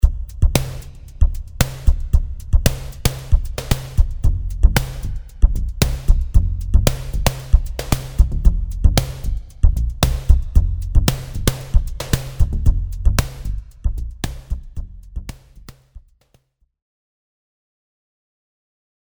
＜デモサウンド＞
今回はキック、スネア、ハイハットを作成してドラムキットを組み、
Spireのシンセベースと共にリズムトラックを作成しています。
ちなみにミックスの際に使用したリバーブはFabFilterのPro-Rです。
ノイズに設定しているオシレータ1をハイパスフィルターに通すことで、シャリシャリした高域成分で金属感を表現しています。